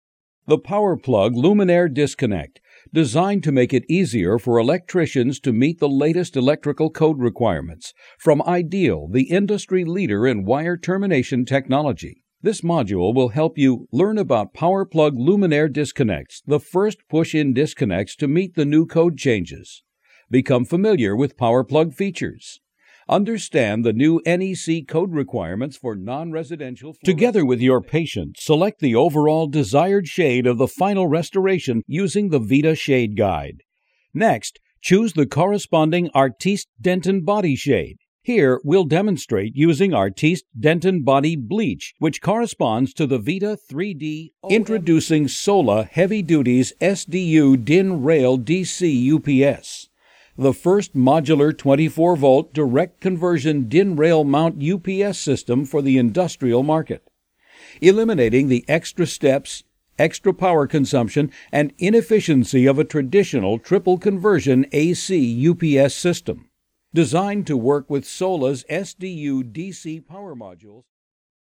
E-Learning Demo